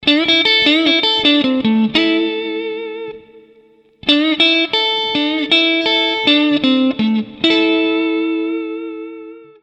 Fraseggio blues 03
Uno dei più classici esempi di bending blues, suonato su di un gruppo di tre corde.
Da notare la chiusura del fraseggio sulla coppia di note E e A della seconda e prima corda.